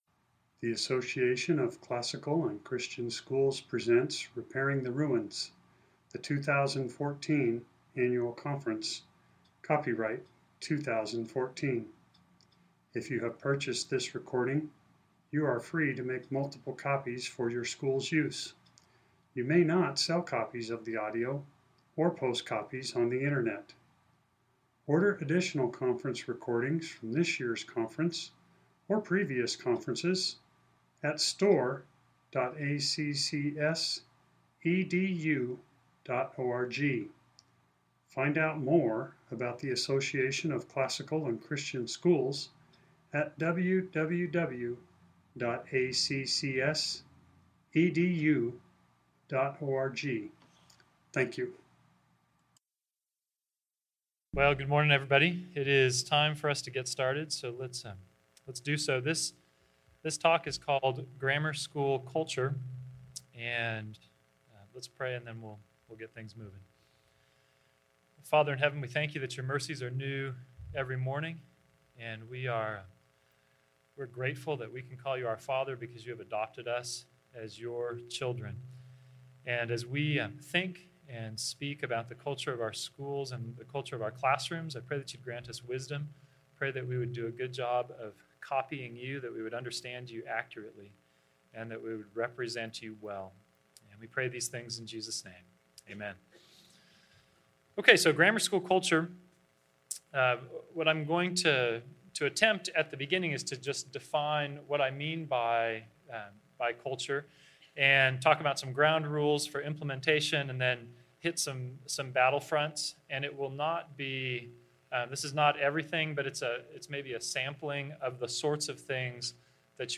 2014 Workshop Talk | 1:07:58 | K-6, Virtue, Character, Discipline